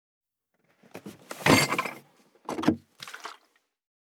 182,地震,引っ越し,荷物運び,段ボール箱の中身,部署移動,ゴロゴロ,ガタガタ,ドスン,バタン,ズシン,カラカラ,ギィ,ゴトン,キー,ザザッ,ドタドタ,バリバリ,カチャン,
効果音荷物運び